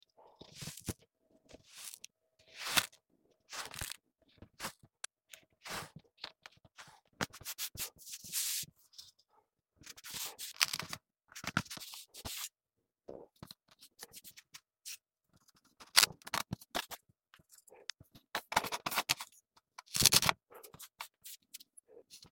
Kinetic sand shape ASMR satisfaisant sound effects free download